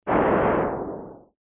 rocket_down.mp3